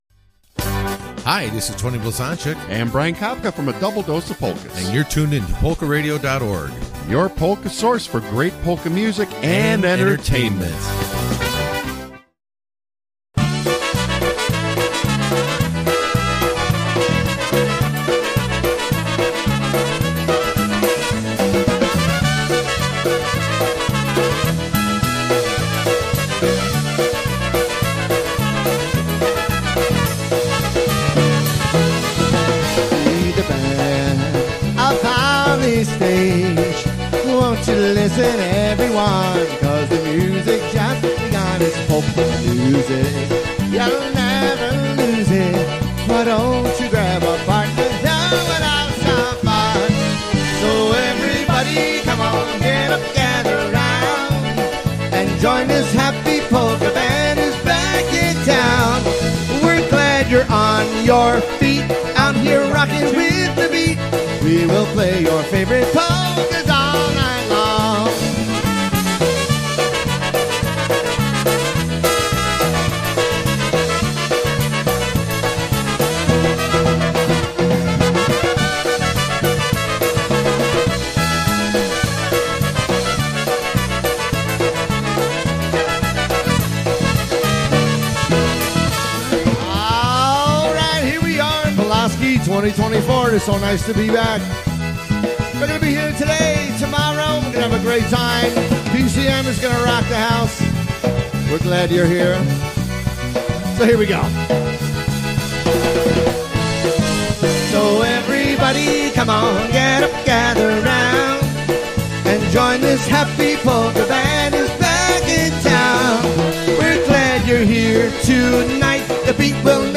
A Polka Show Not for the Faint of Heart!